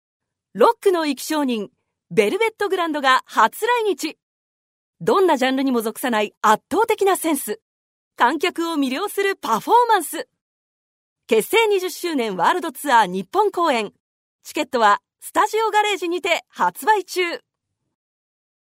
Voice Sample
CM1